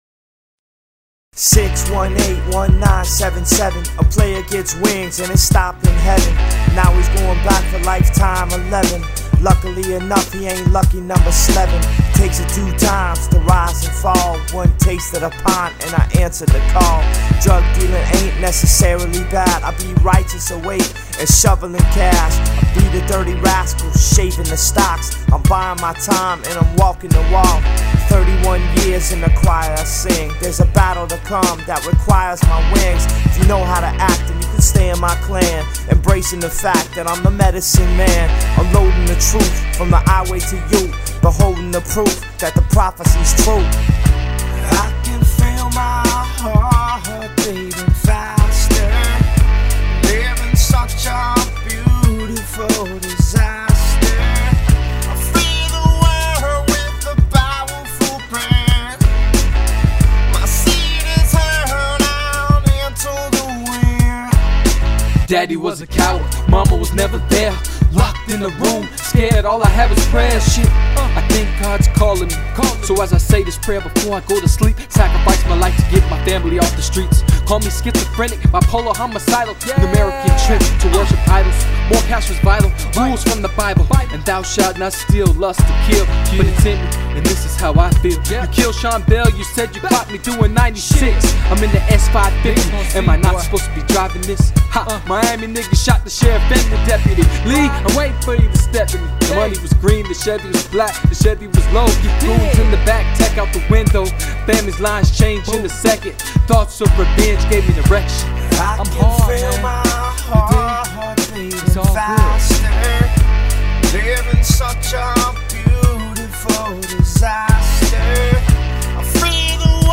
Gangsta Rap
hip hop